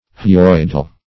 Hyoideal \Hy*oid"e*al\